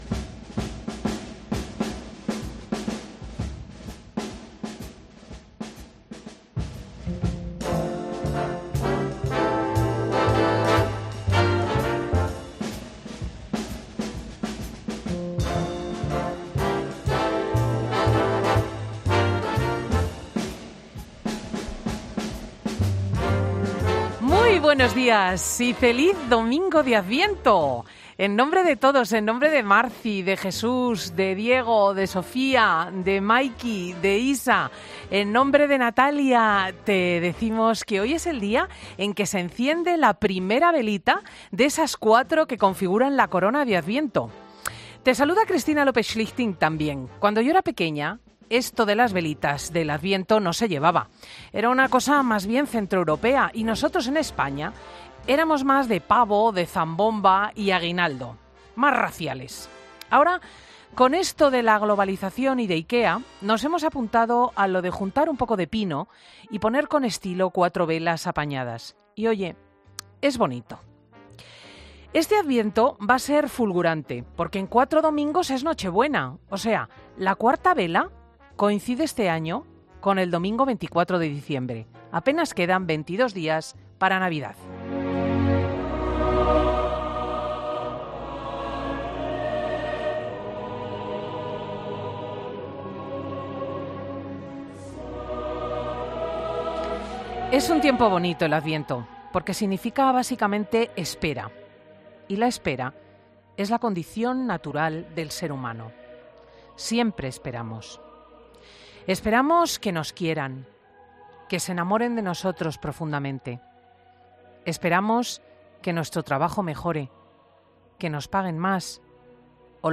Monólogo de Cristina López Schlichting
El editorial de Cristina López Schlichting del domingo 3 de diciembre de 2017